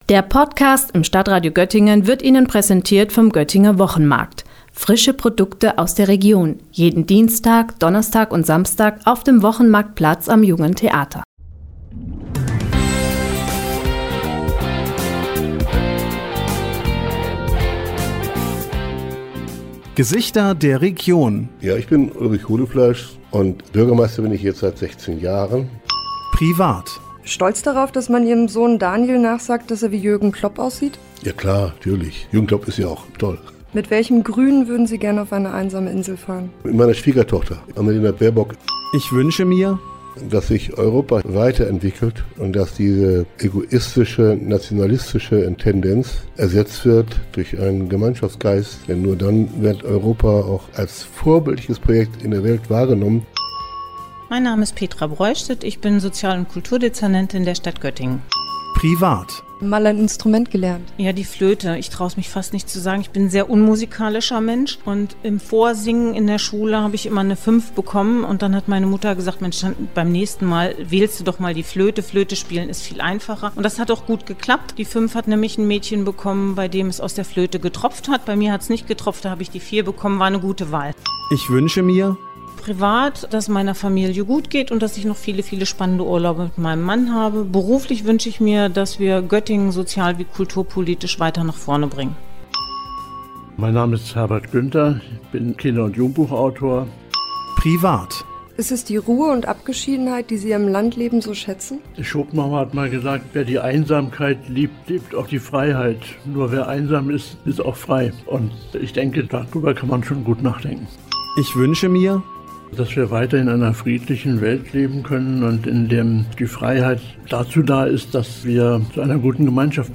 Zum Jahresabschluss wollen wir diese Menschen noch einmal zu Wort kommen lassen.
Heute sind unter anderem unsere drei stellvertretenden Bürgermeister mit dabei.